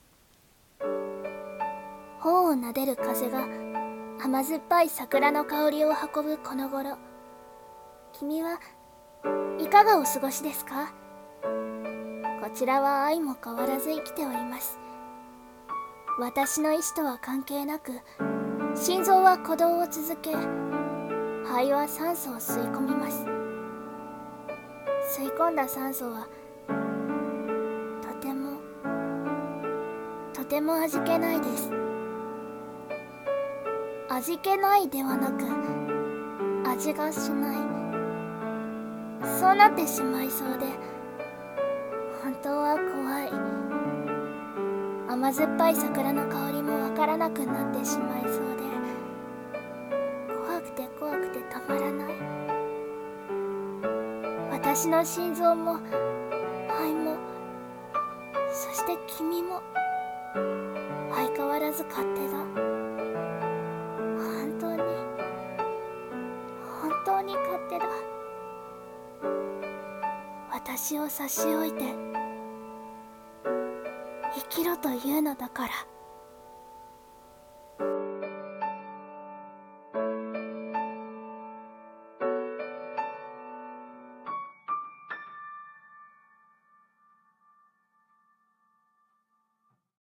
【声劇】この世界の匂いと味は